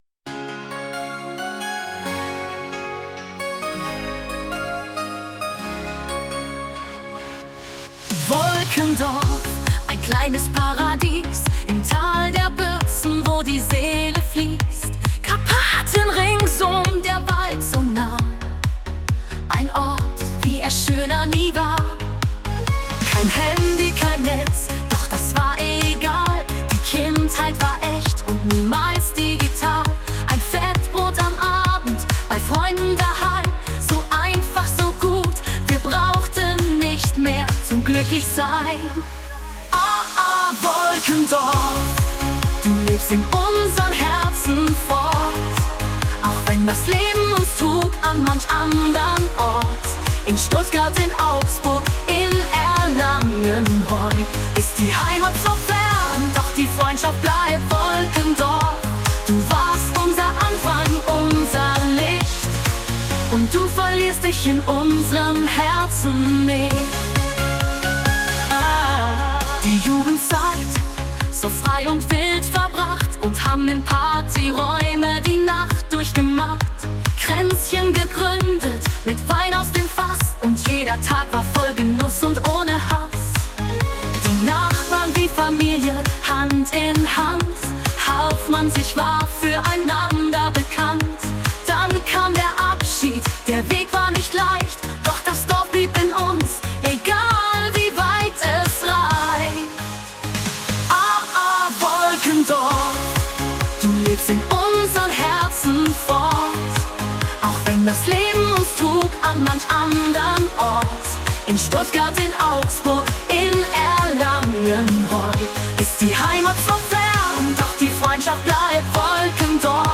Schlager-Hit